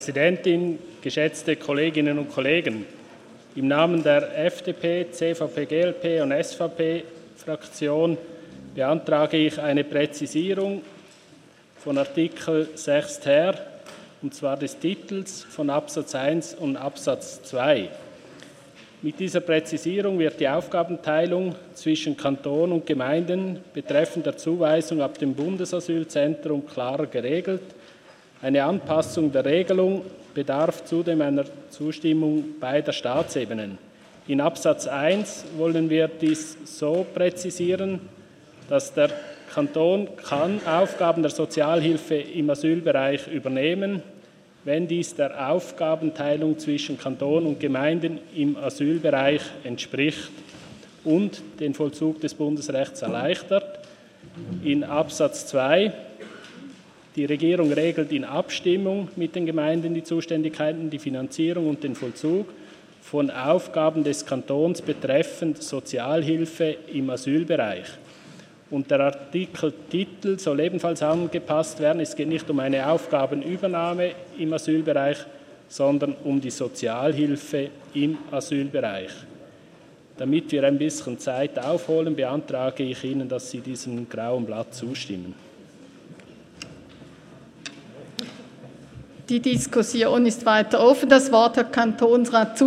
17.9.2018Wortmeldung
Session des Kantonsrates vom 17. bis 19. September 2018